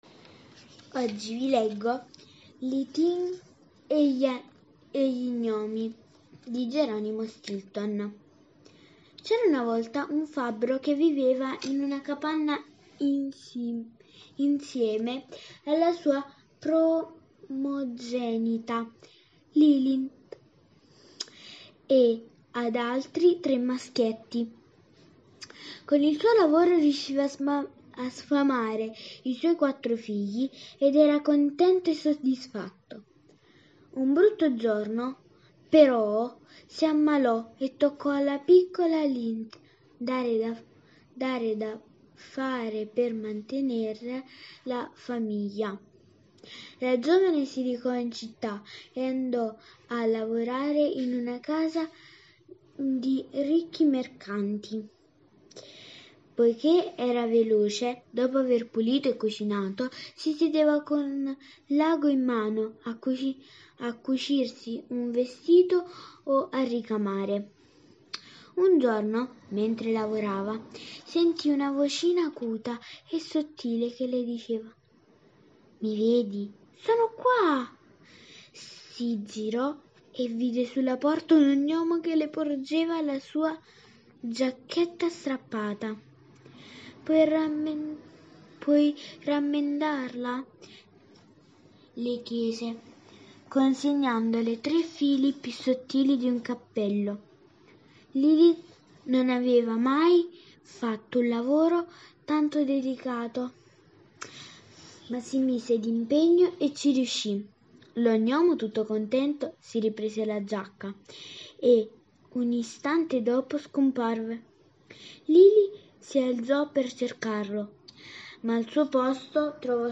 Le favole della buonanotte
Questa sera vi leggo la fiaba israeliana “Lilith e gli gnomi” tratto dal libro di fiabe “le fiabe più belle del mondo” di Geronimo Stilton